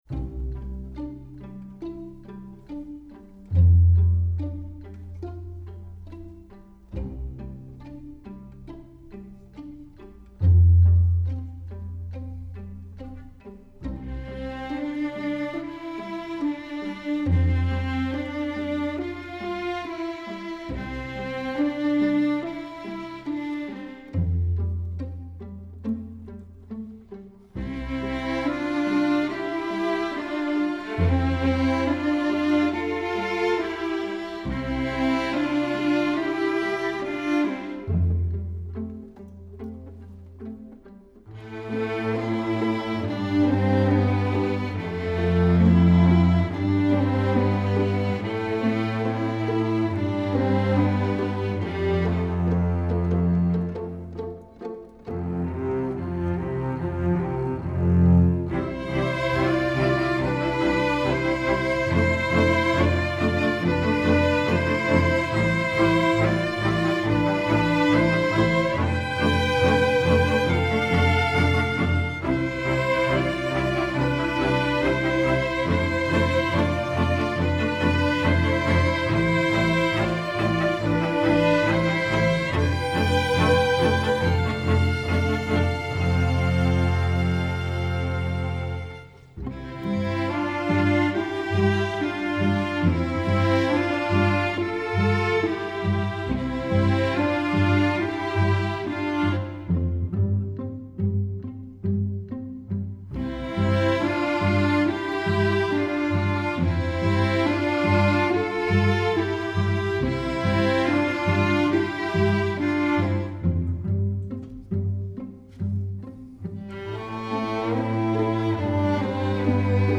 broadway, film/tv, pop, rock, movies